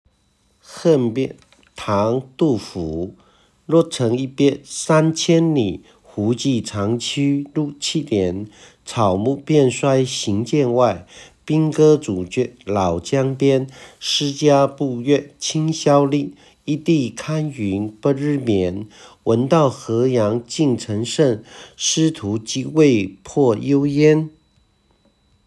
讀音說明：「六」古音讀「廬谷切，陸，入聲」。